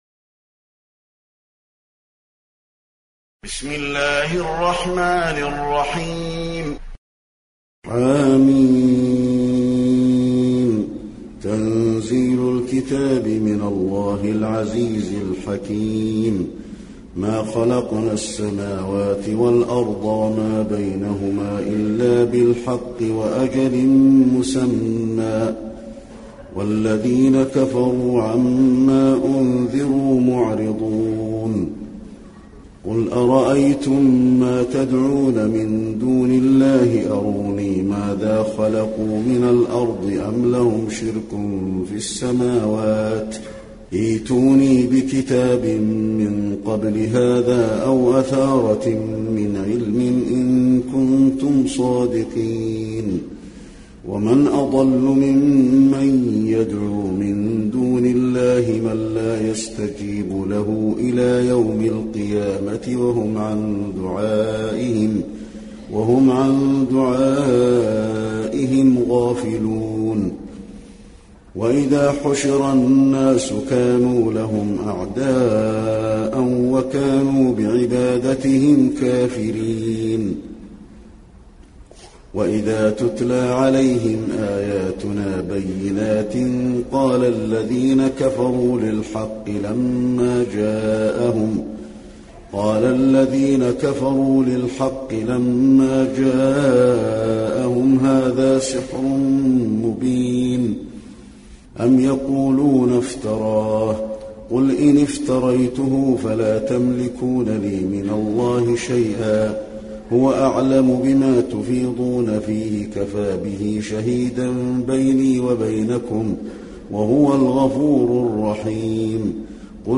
المكان: المسجد النبوي الأحقاف